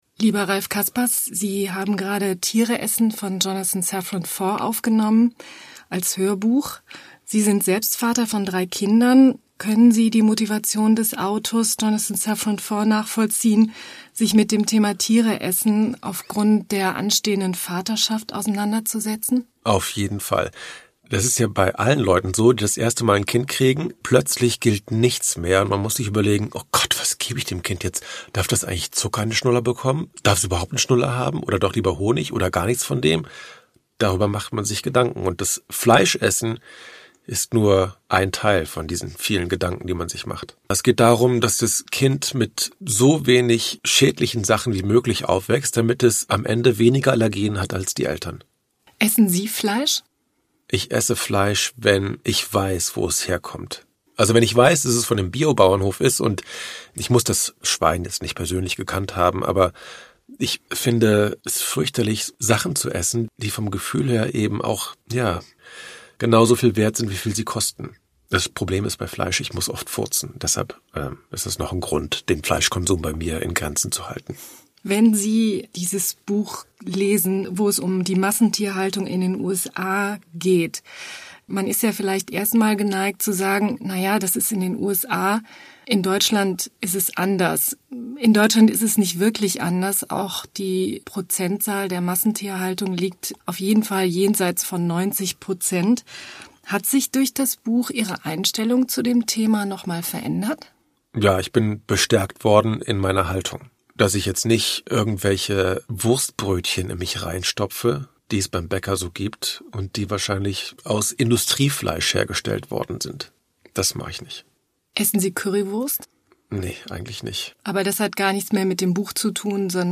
Ralph Caspers (Sprecher)
2010 | Gekürzte Lesung
(...) angenehm gelesen von TV-Moderator Ralph Caspers (Wissen macht Ah!)."